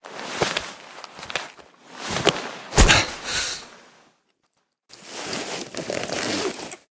medkit_use.ogg